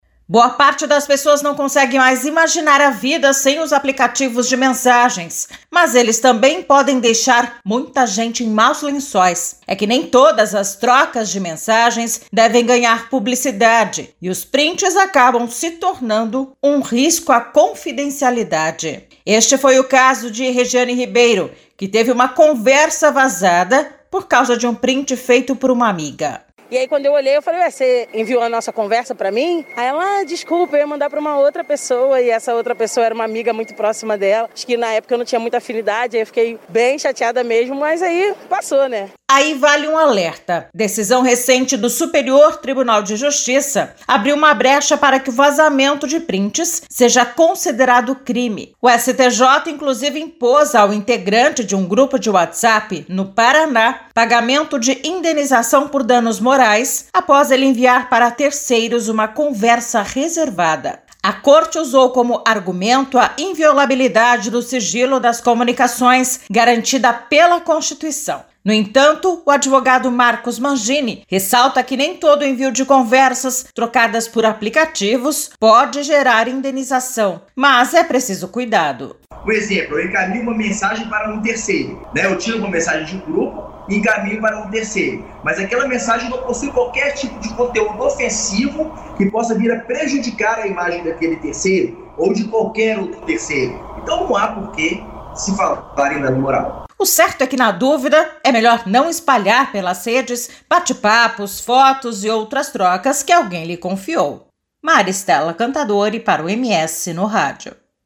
Saiba mais no Boletim